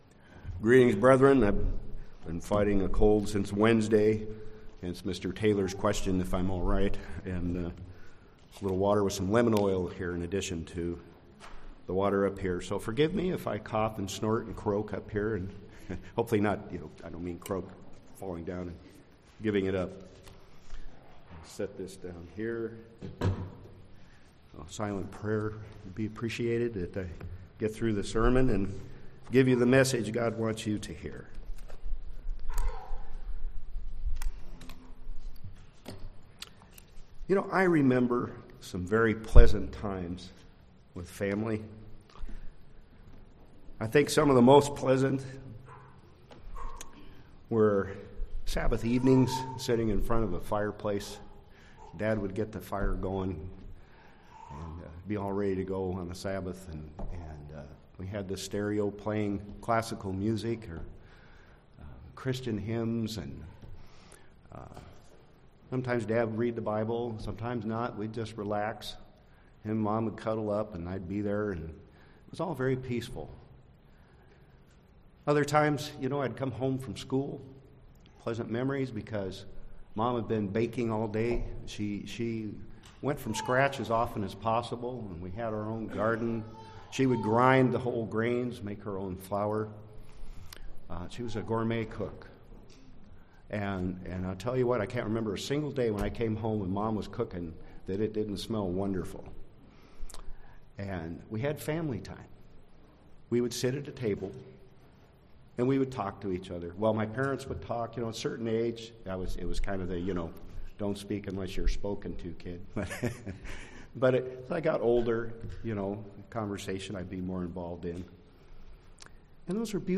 Listen to this sermon to find out who your spiritual family is and how to show that you are thankful for them.